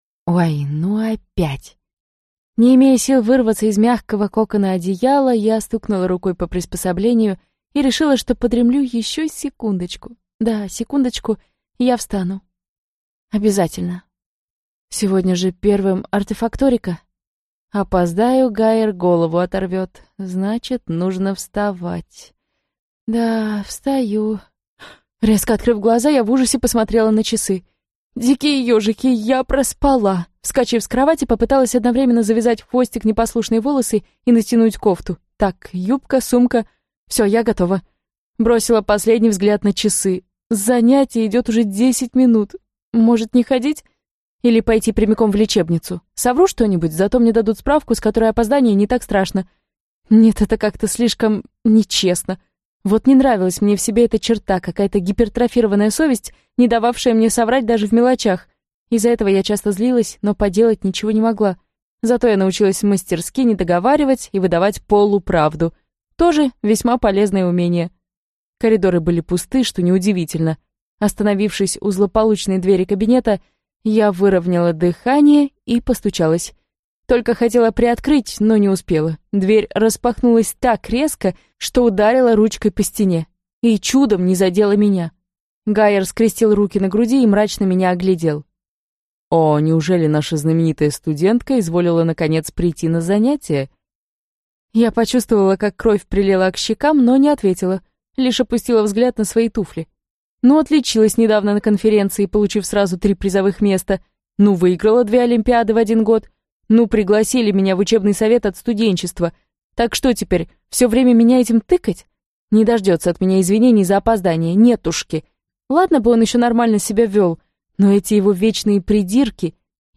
Аудиокнига Декан в моей голове | Библиотека аудиокниг
Прослушать и бесплатно скачать фрагмент аудиокниги